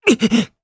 Evan-Vox_Damage_jp_02.wav